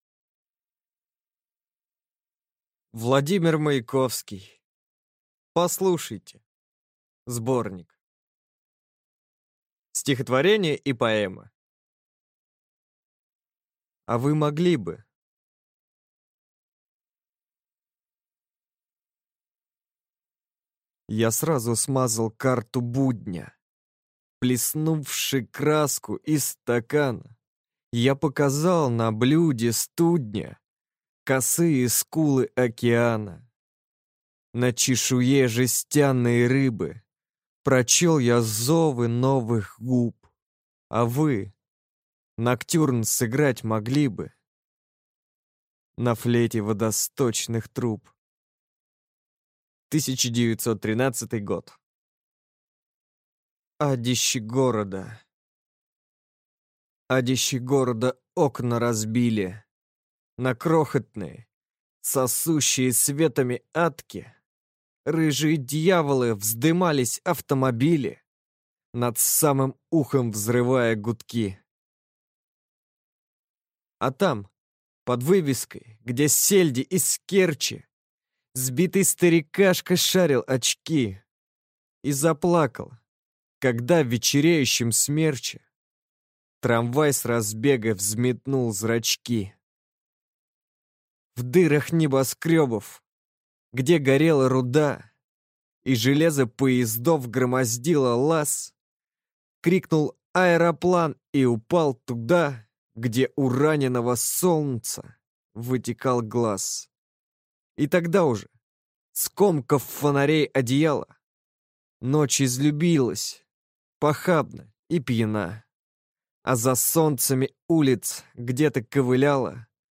Аудиокнига Послушайте! (сборник) | Библиотека аудиокниг